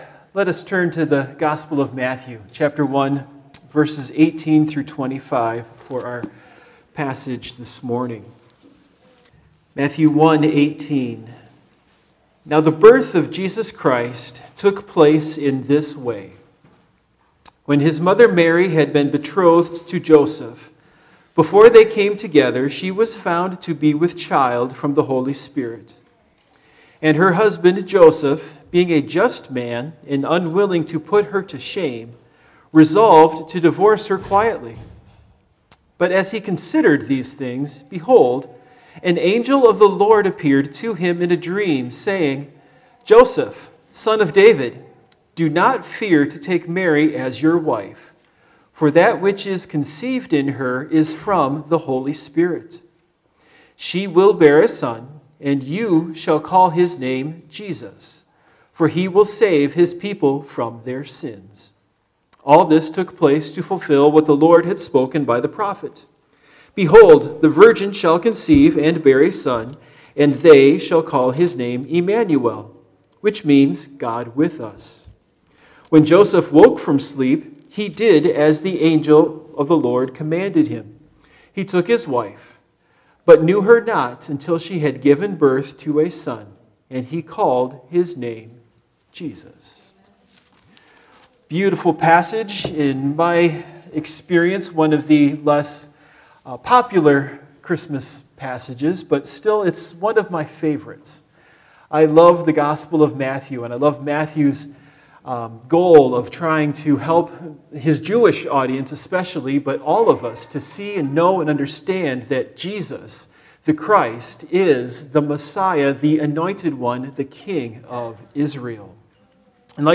Passage: Matthew 1:18-25 Service Type: Morning Worship